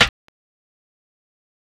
kits/OZ/Snares/Sn (PanCake).wav at main
Sn (PanCake).wav